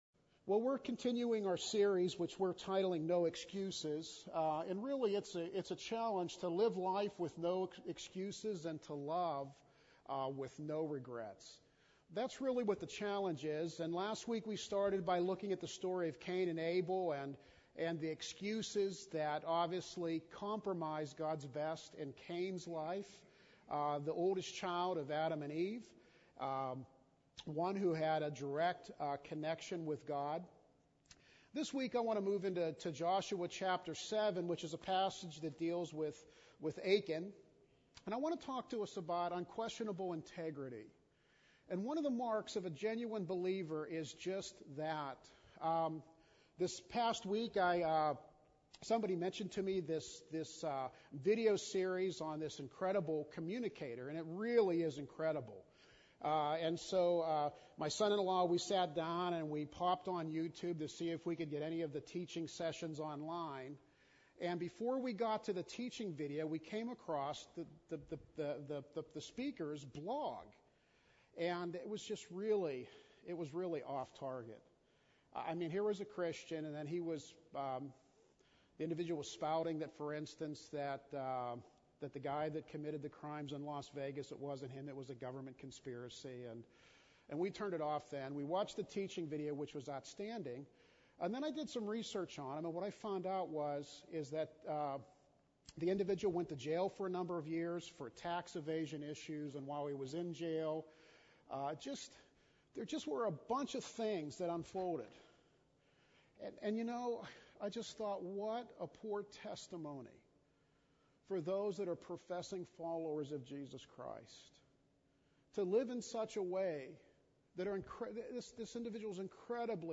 Service Type: Sunday AM Worship Service